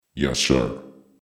알림음 8_Robot_Yessir.mp3